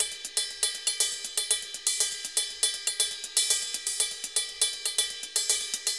Ride_Candombe 120_2.wav